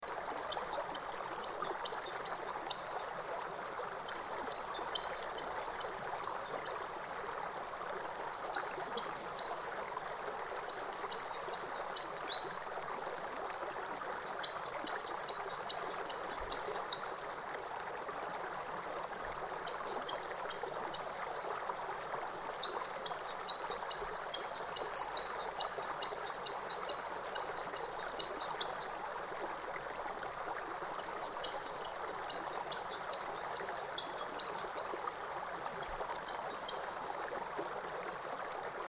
Bird Calls & Forest Stream Sound Effect
Authentic, organic bird calls blend with a gentle forest stream to create a natural sound effect that supports relaxation and stress relief.
Audio loop.
Bird-calls-forest-stream-sound-effect.mp3